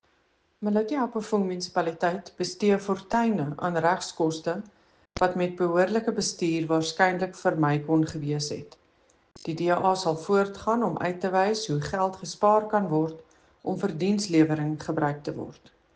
Afrikaans soundbite by Cllr Eleanor Quinta and